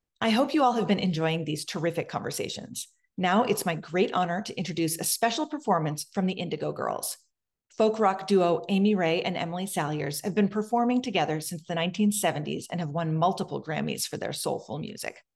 (captured from a crowdcast live stream)
02. introduction (0:15)